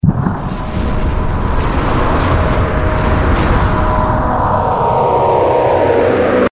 Root > sounds > weapons > hero > phoenix
super_nova_cast.mp3